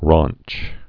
(rônch, ränch)